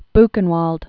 (bkən-wôld, -ən-vält)